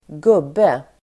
Ladda ner uttalet
Uttal: [²g'ub:e]